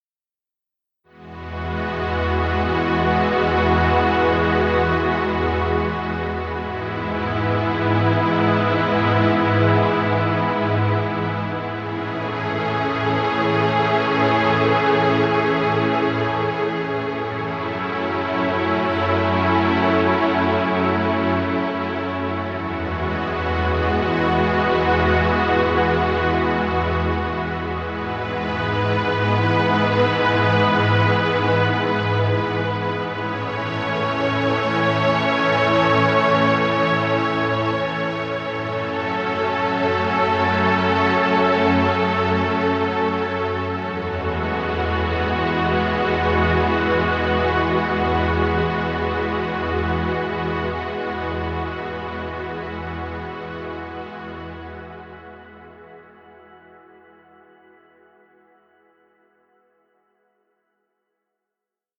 Relaxing music. Background music Royalty Free.
Stock Music.